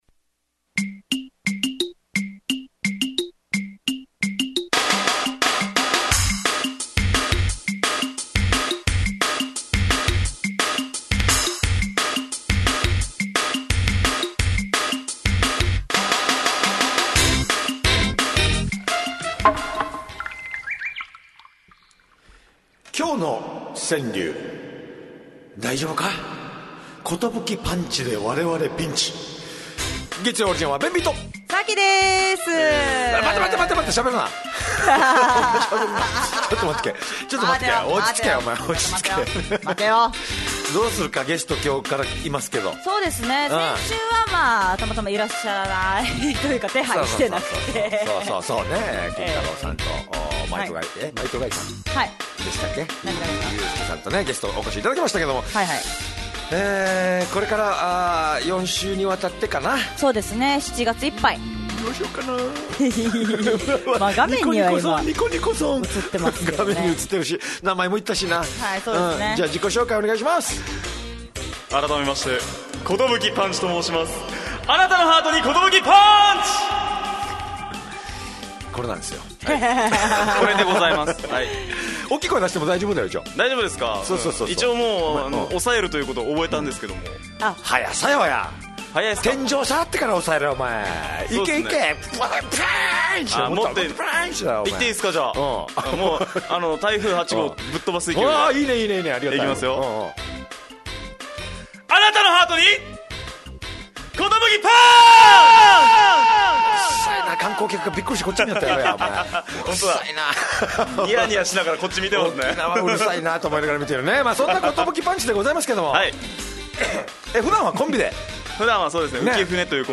fm那覇がお届けする沖縄のお笑い集団・オリジンメンバー出演のバラエティ番組の